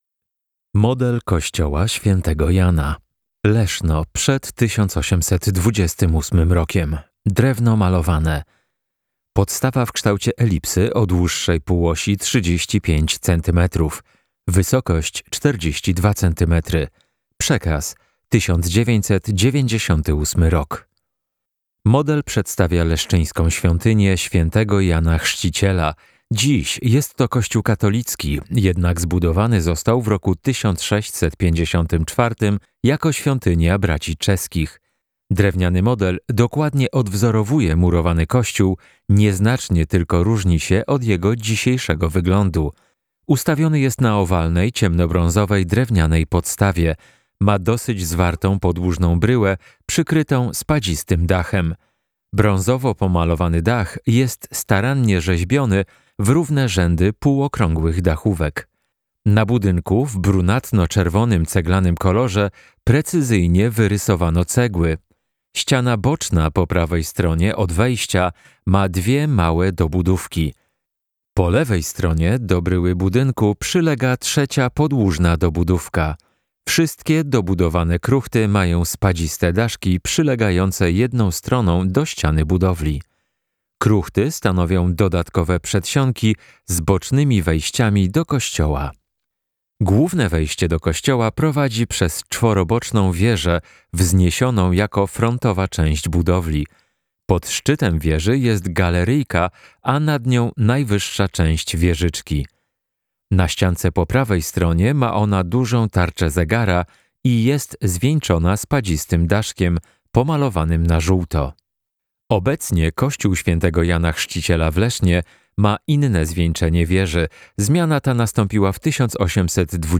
Audiodeskrypcja -